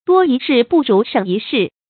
多一事不如省一事 注音： ㄉㄨㄛ ㄧ ㄕㄧˋ ㄅㄨˋ ㄖㄨˊ ㄕㄥˇ ㄧ ㄕㄧˋ 讀音讀法： 意思解釋： 指不管閑事，事情越少越好。